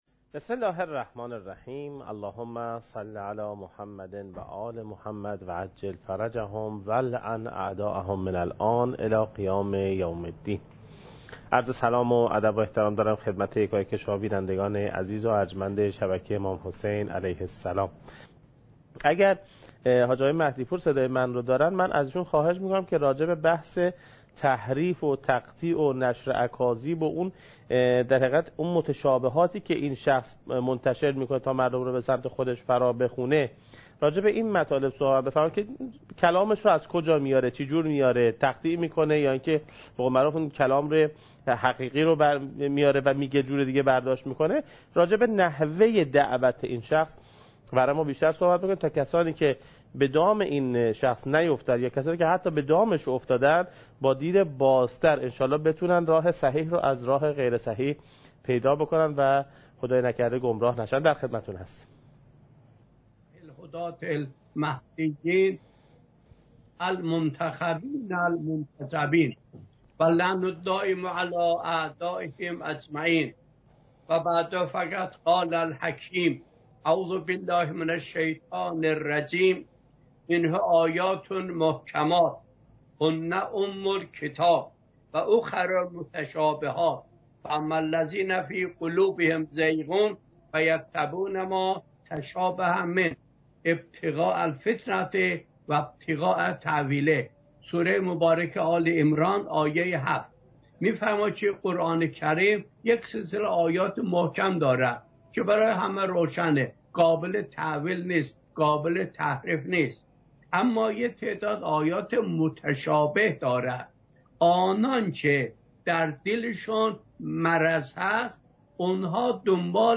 حجم: 9.37 MB | زمان: 39:36 | تاریخ: 1441هـ.ق | مکان: کربلا